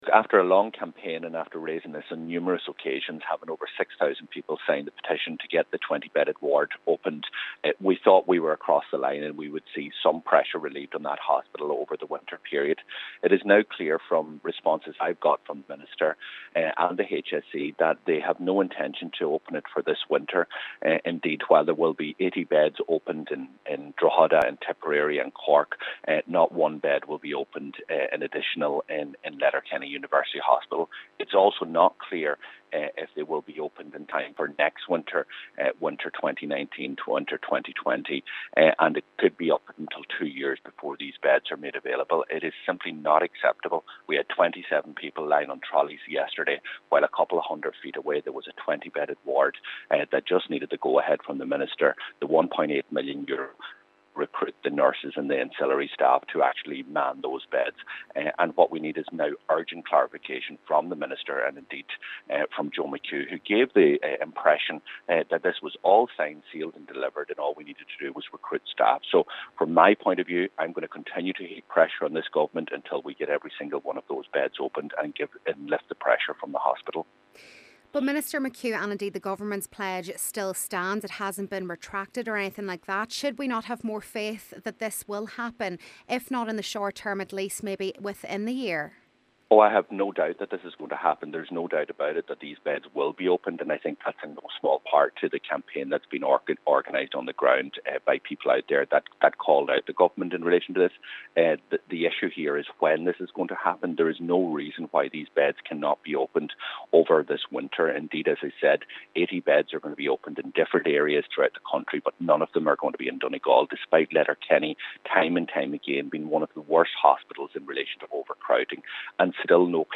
However Donegal Deputy Pearse Doherty believes there are still serious questions over the government’s determination to deliver on this promise: